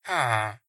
sounds / mob / villager